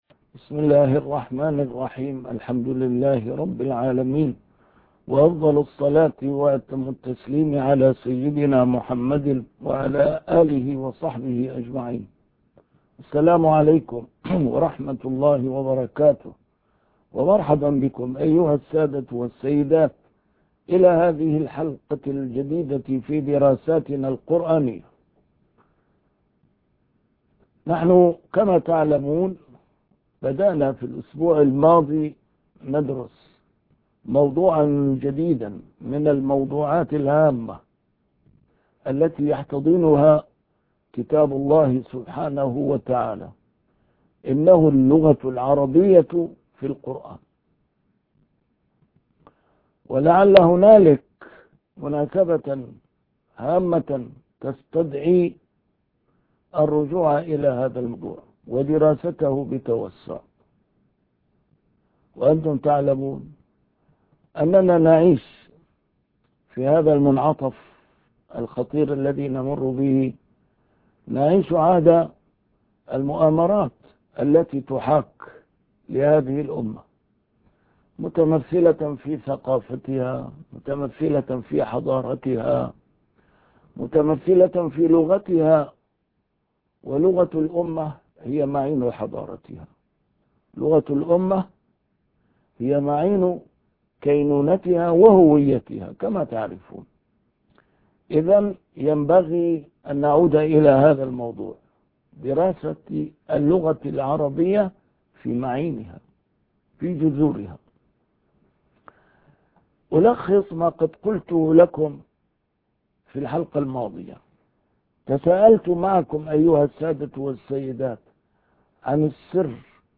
درسات قرآنية الجزء الثاني - A MARTYR SCHOLAR: IMAM MUHAMMAD SAEED RAMADAN AL-BOUTI - الدروس العلمية - علوم القرآن الكريم - 2- اللغة العربية في القرآن